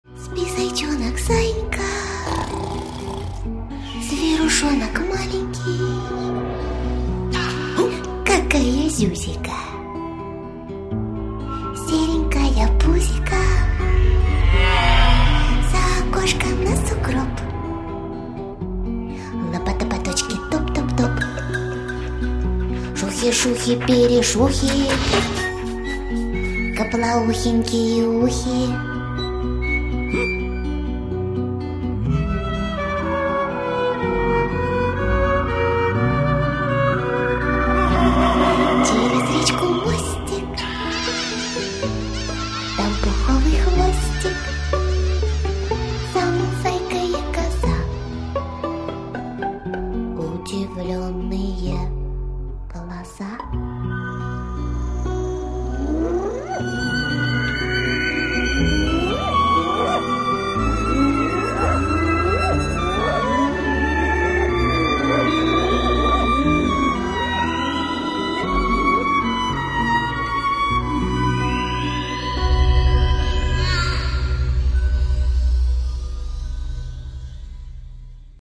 • Качество: 224, Stereo
милые
колыбельная
детская